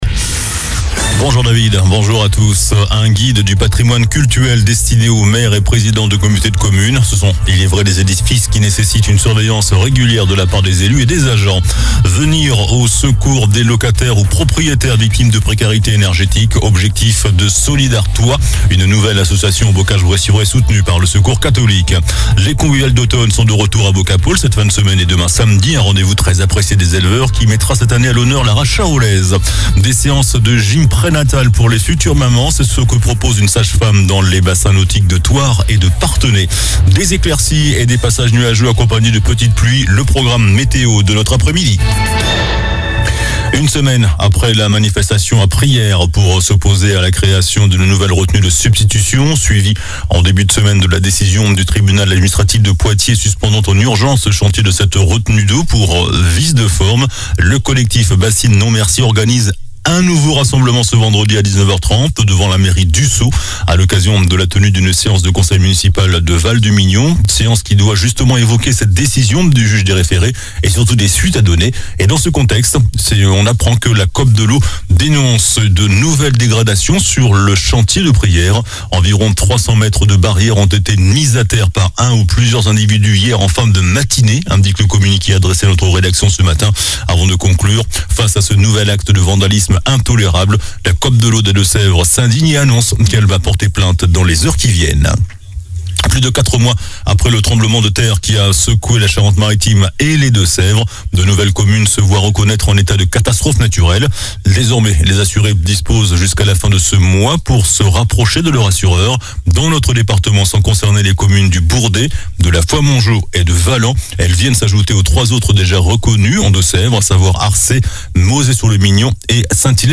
JOURNAL DU VENDREDI 03 NOVEMBRE ( MIDI )